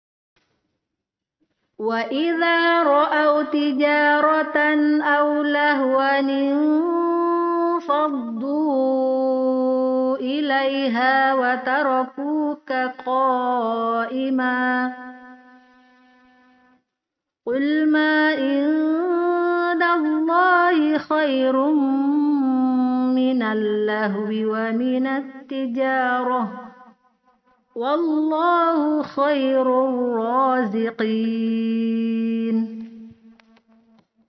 Nun Wiqayah adalah Nun yang muncul ketika tanwin bertemu dengan hamzah washal. Oleh karena itu, apabila ada tanwin bertemu hamzah washal, tanwin nya berubah menjadi harakat biasa dan muncul lah nun berharakat kasrah yang dinamakan Nun Wiqayah.